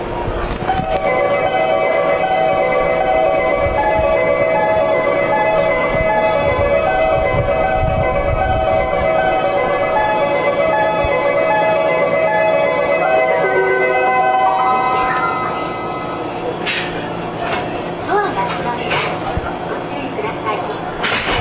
周辺を配慮してか、メロディーの音量が非常に小さいです。
Gota del Vient 音量小さめ